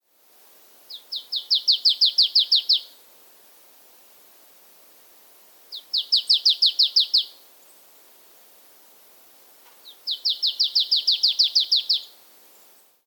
Art: Løvmeis (Poecile palustris)
Sang
Lyder: Løvmeisa har ulike lyder, blant annet en eksplosiv «pi-tju»-lyd, en gjentatt «tsjiu-tsjiu-tsjiu» og en ensformig sang: «tjipp-tjipp-tjipp-tjipp», nesten som om du vrir om på tenningen på en bil, men ikke får helt start.